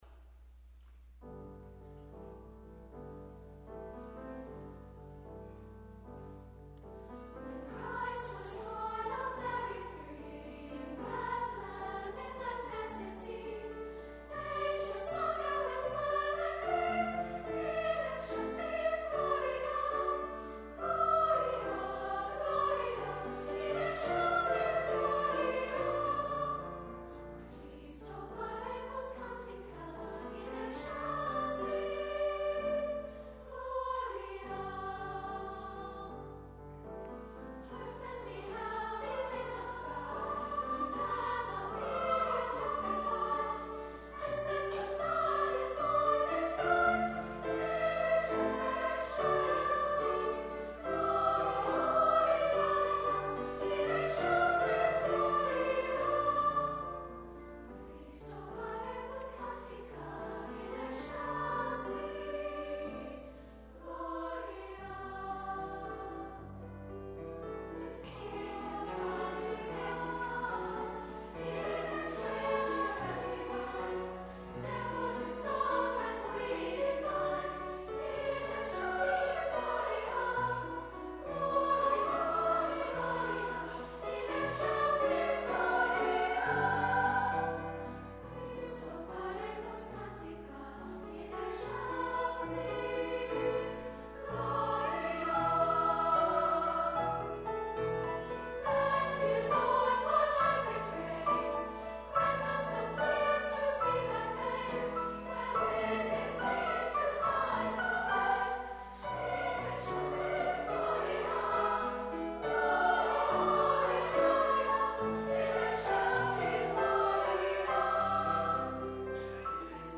Carol.
Instrumente: Klavier (1)
Tonart(en): G-Dur